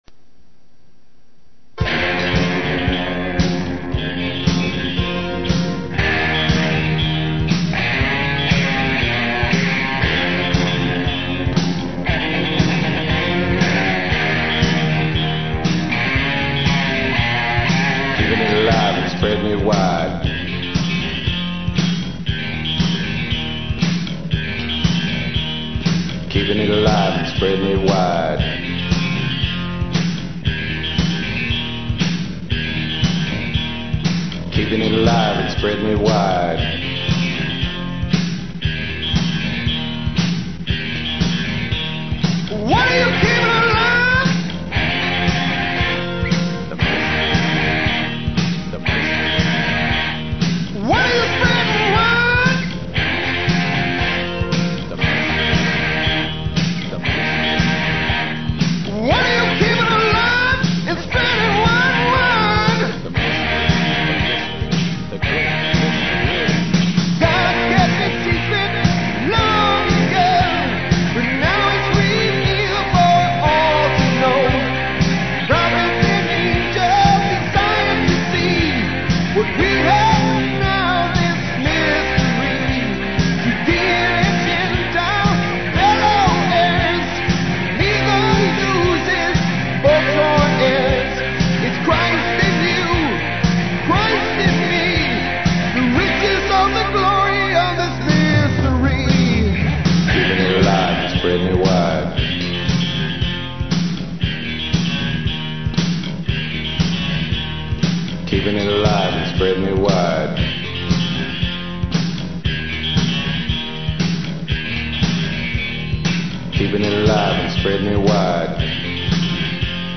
Vocals, guitars, keyboards
Bass, guitar, background vocals
Drums, percussion